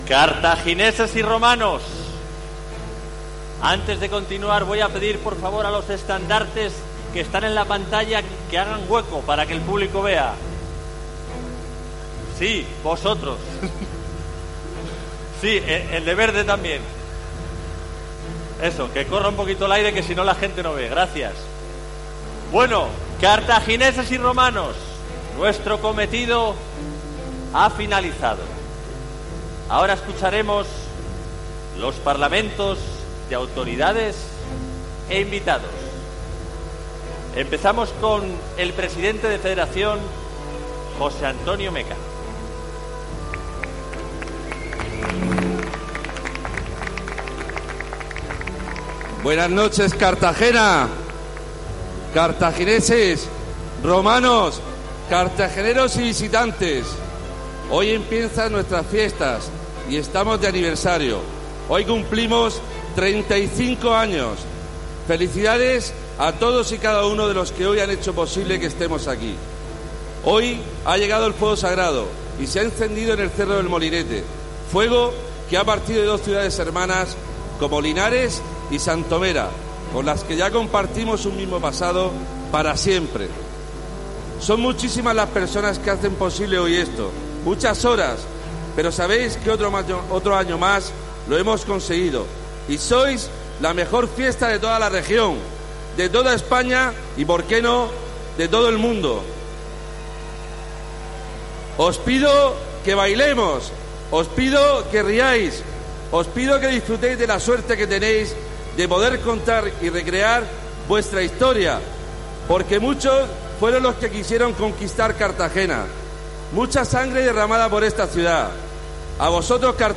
Audio: Preg�n de Carthagineses y Romanos. (MP3 - 9,51 MB)
Cartagena revive hasta el 29 de septiembre su historia milenaria con la gran fiesta del Mediterráneo: Carthagineses y Romanos. Así lo han anunciado los jugadores del Jimbee Cartagena de fútbol sala en el pregón que han ofrecido en la plaza del Ayuntamiento este viernes, 20 de septiembre.